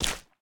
resin_break5.ogg